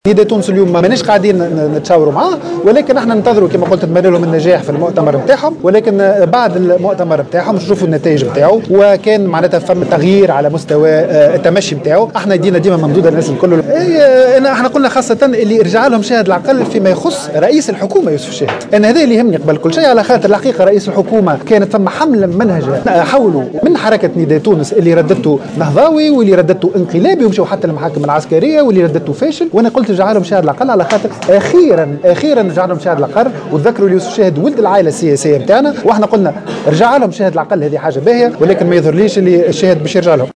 وأضاف في تصريح اليوم على هامش ندوة صحفية عقدتها حركة "تحيا تونس" للإعلان عن انضمام عدد من الشخصيات الوطنية لصفوفها : " نداء تونس رجعلهم شاهد العقل أمّا مانتصورش الشاهد باش يرجعلهم".وأوضح العزابي أن الشاهد قد تعرّض إلى "حملة ممنهجة" من نداء تونس وذلك بعد وصفه بـ"النهضاوي" و"الانقلابي" و"الفاشل"، وفق تعبيره.